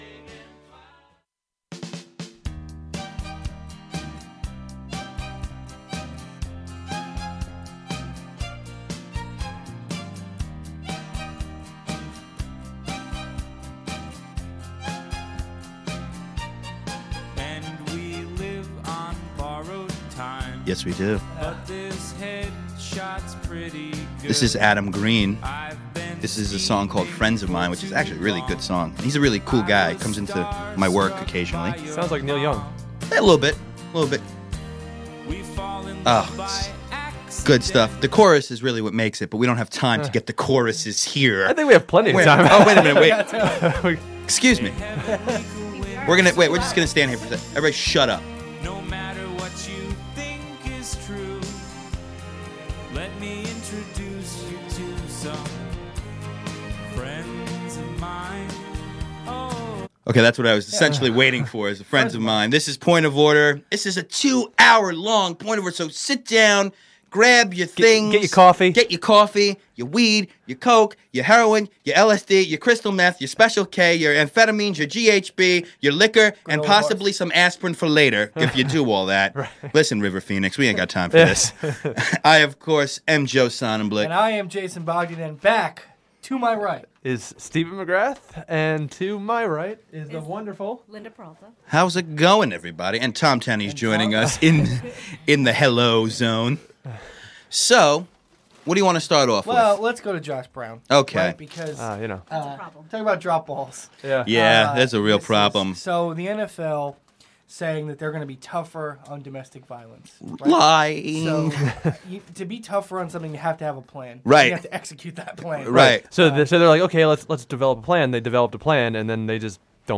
We touch on the Josh Brown Situation, the Derrick Rose situation, the Ryan Fitzpatrick situation and somehow the Cubs. We took a couple of calls.